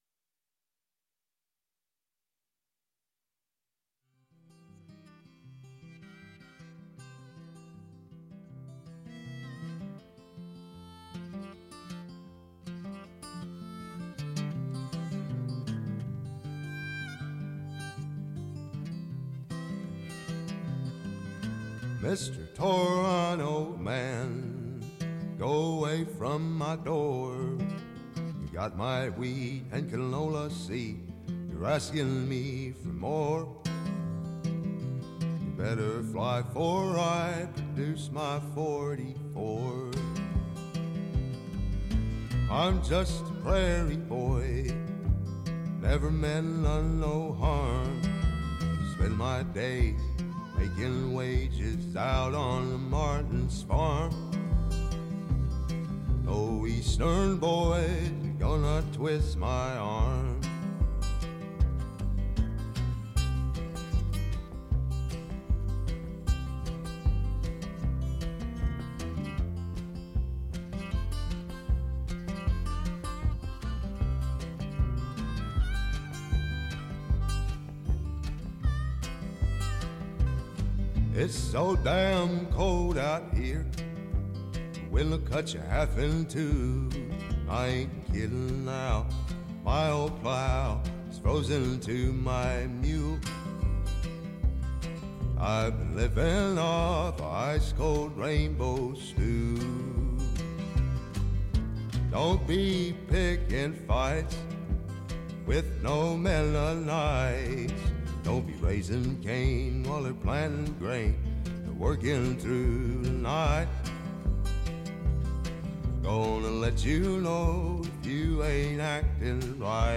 He is an exceptional banjo player with captivating original songs.